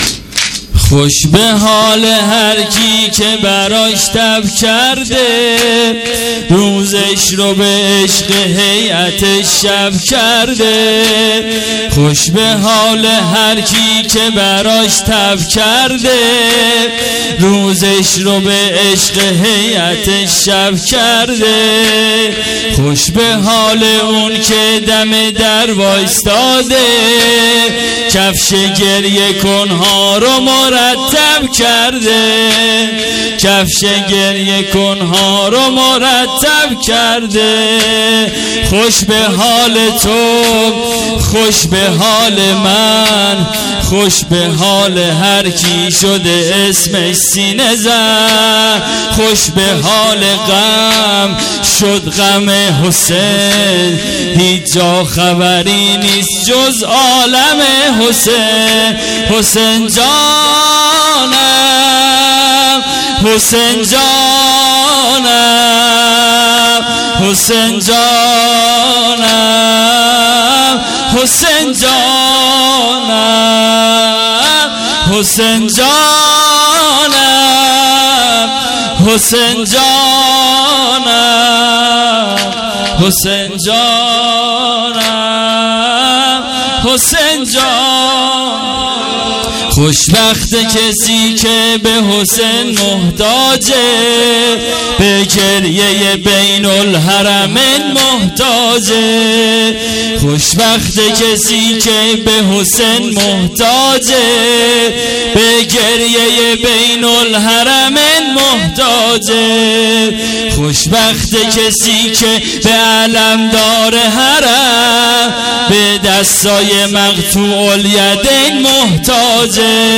شب هفتم محرم الحرام 1399